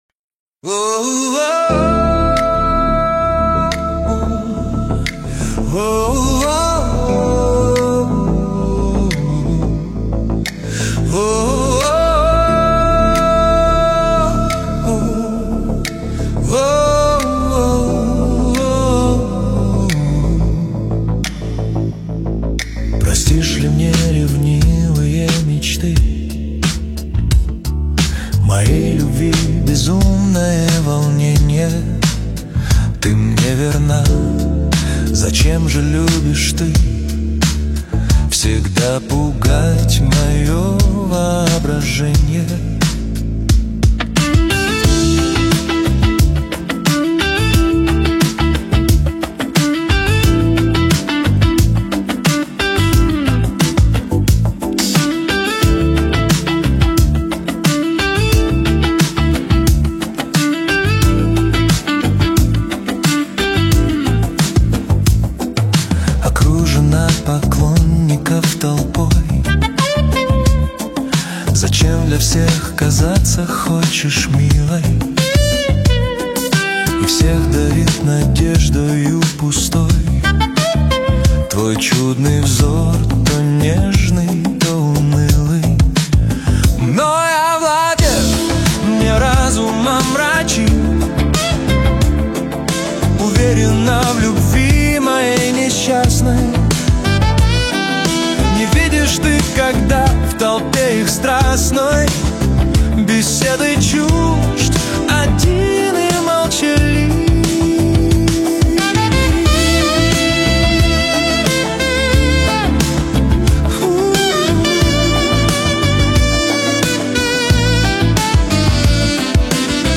• Качество: 320 kbps